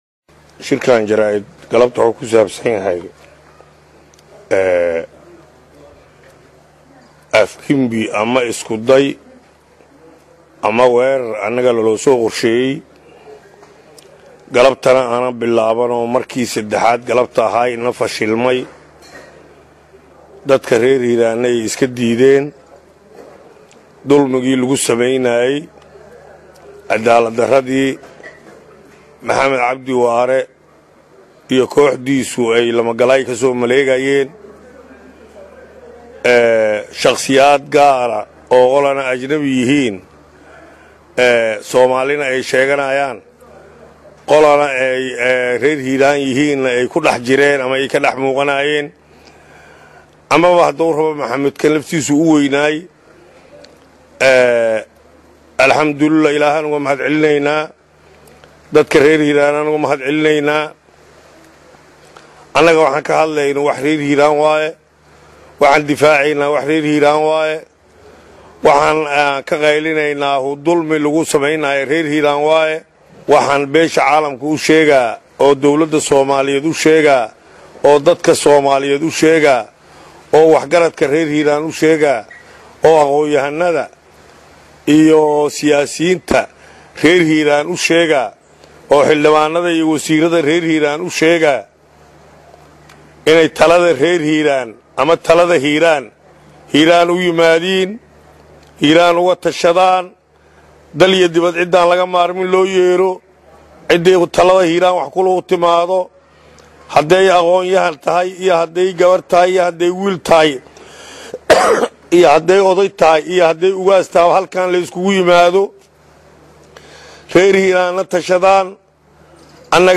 Shir Jaraa’id uu xalay ku qabtay Baladweynhe ayuu ku sheegay in shalay la soo weeraray, isla markaana Ciidamo Soomaali iyo Ajnabi ah ay soo weerareen si ay u dilaan balse uu dilkaasi ka badbaaday.
Hoos ka dhageyso codkiisa